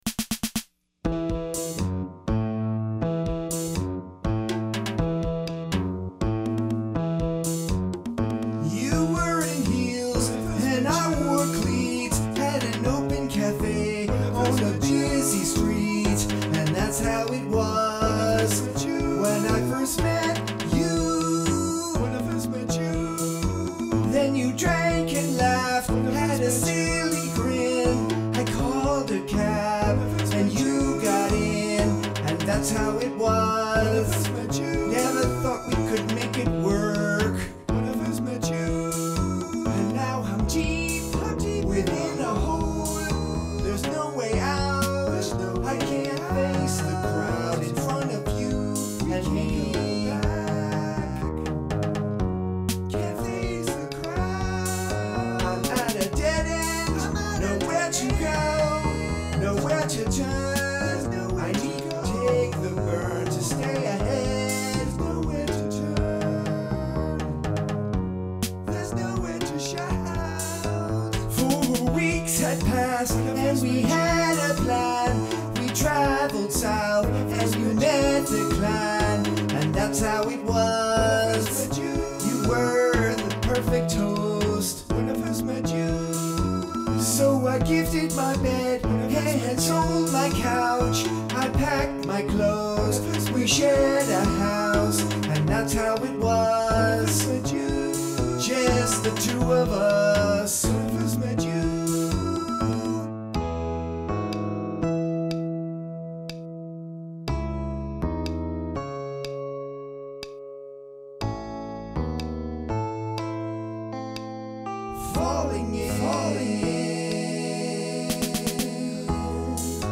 guitar, bass guitars
percussion (pending)
keyboards (pending)